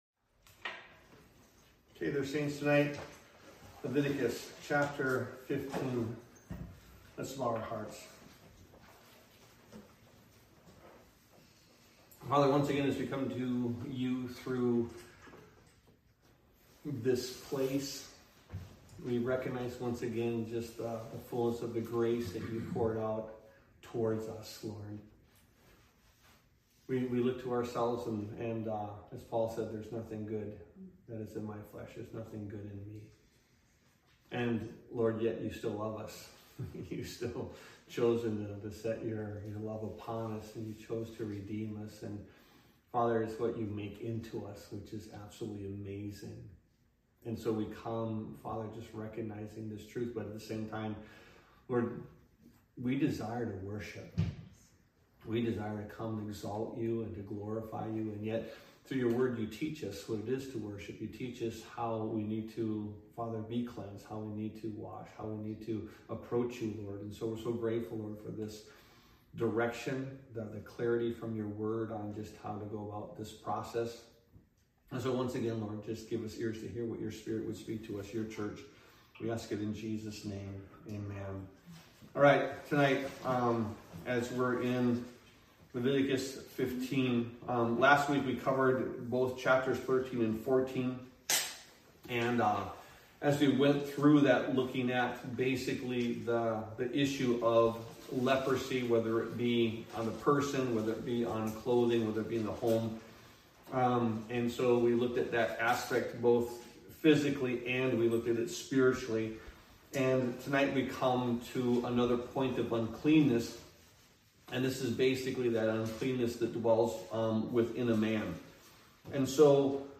Sermons | Calvary Chapel Milwaukee